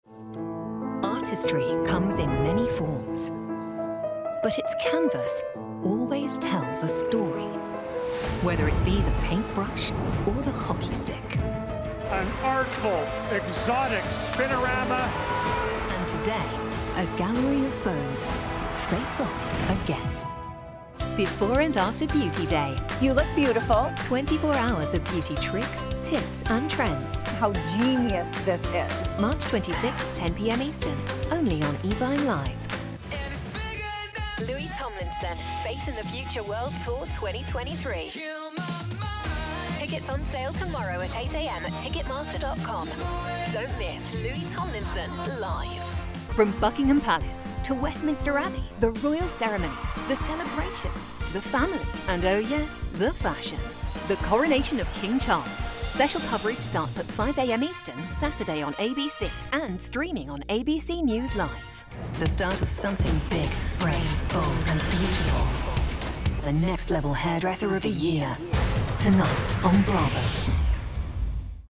Inglés (Reino Unido)
Micrófono de condensador Neumann TLM 103
Cabina insonorizada con calidad de transmisión con paneles acústicos GIK